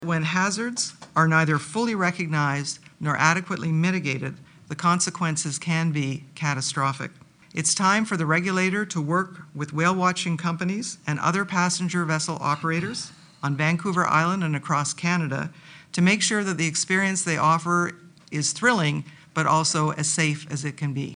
TSB Chair Kathy Fox says while incidents like this are rare, Transport Canada needs to work more closely with passenger vessel operators.